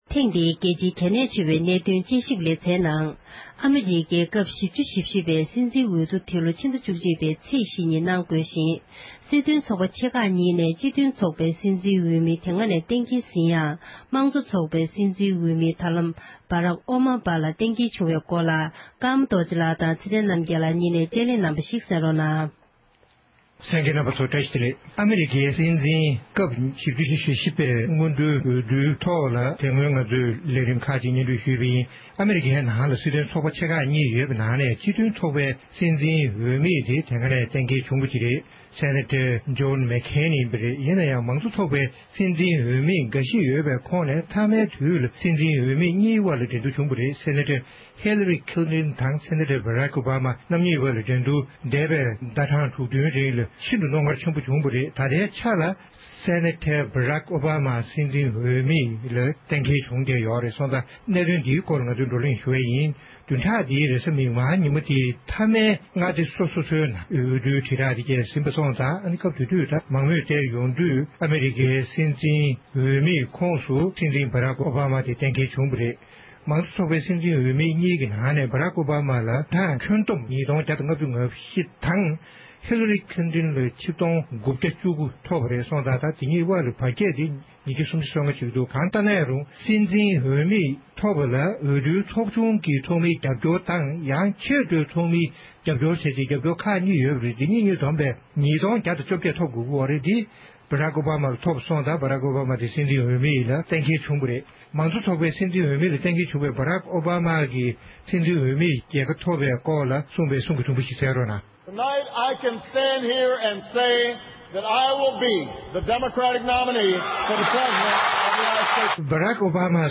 བགྲོ་གླེང་གནང་བ་ཞིག་གསན་རོགས་གནང༌༎